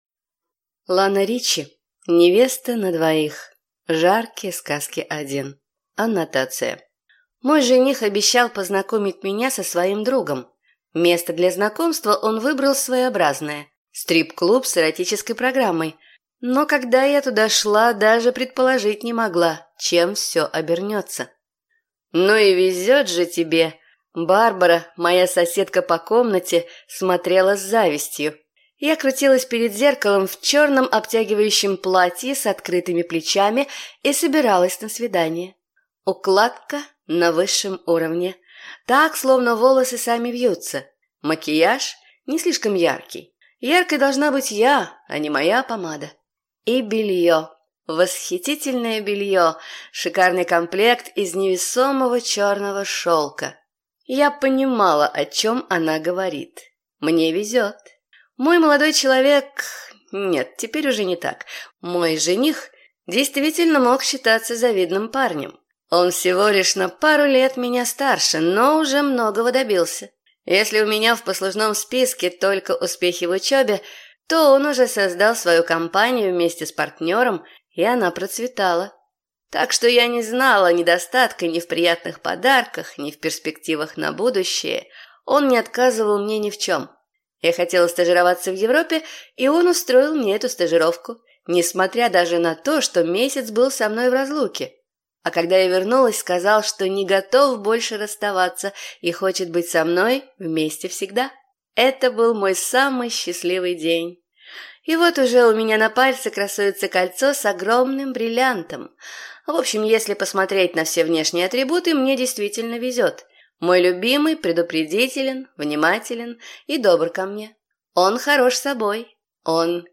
Аудиокнига Невеста на двоих | Библиотека аудиокниг
Прослушать и бесплатно скачать фрагмент аудиокниги